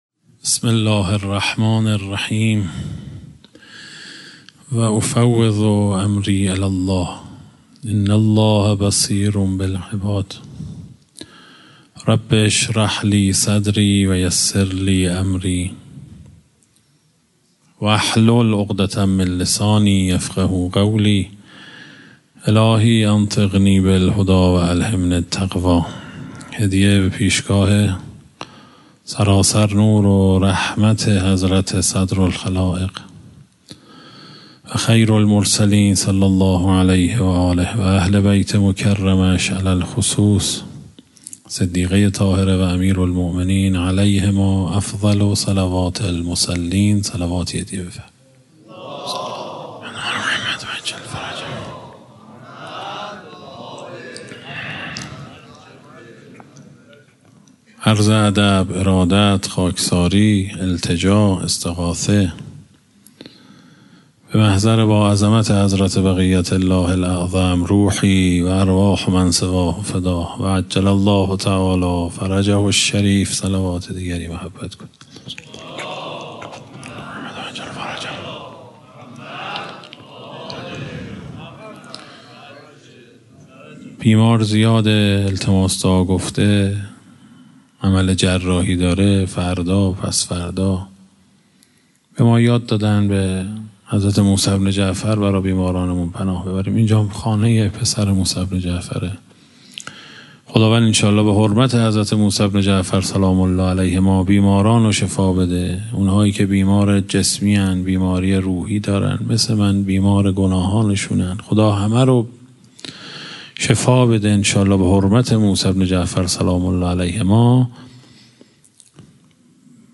به مناسبت ایام فاطمیه در آستان مقدس امامزاده صالح علیه السلام تجریش